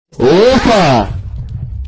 soundboard, meme, “OPA!” loud excited shout, funny energetic grandfather or hype reaction sound effect